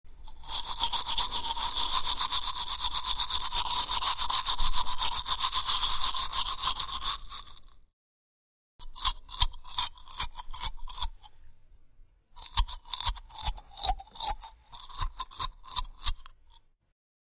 Трение щетки о поверхность зубов